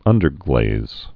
(ŭndər-glāz)